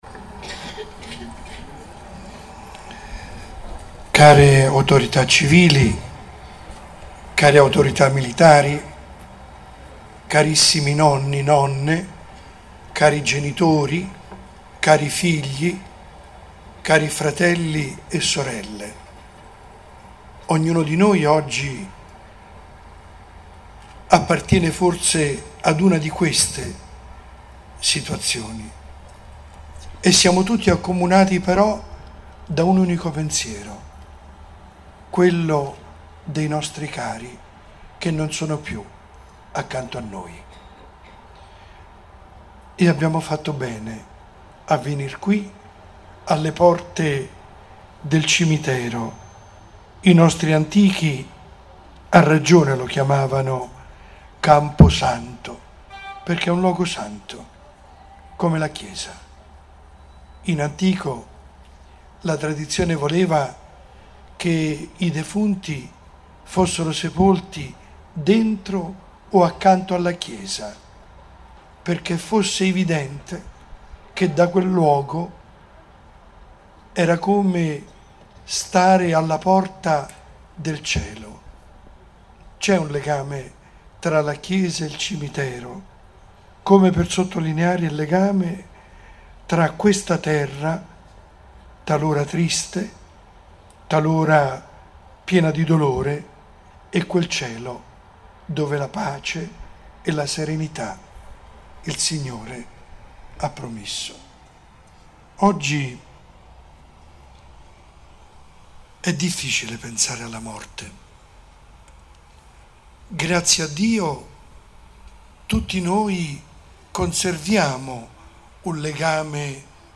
messacimitero.mp3